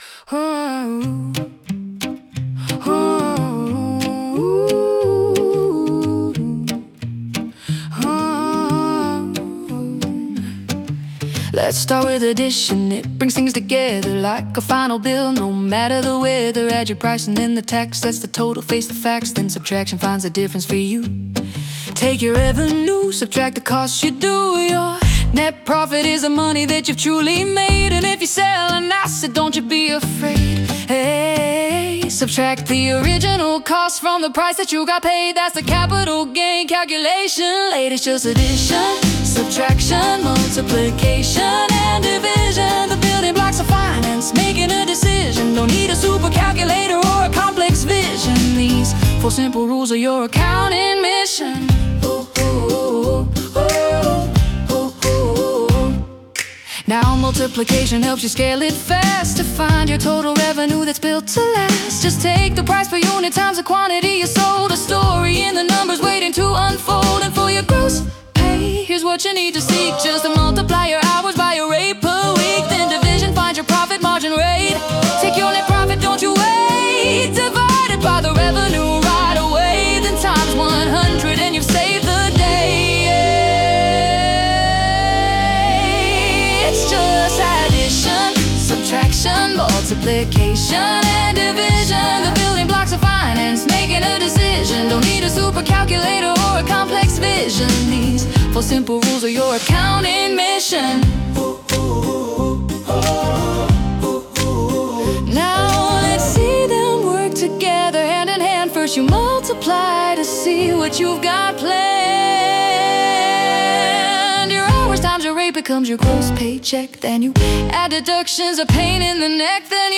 The Sing Along Experience